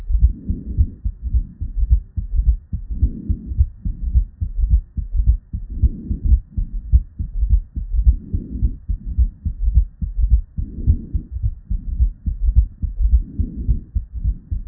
What you’re hearing is a booming gallop. Our patient is so tachycardic it’s tough to distinguish between an S3 and an S4.
Our patient has an S4 gallop.